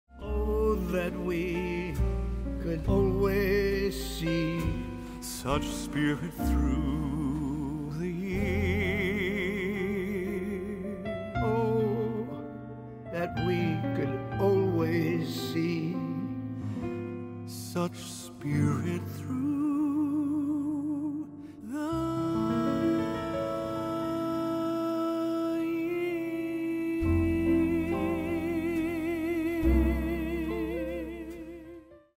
A timeless duet to warm your holiday season.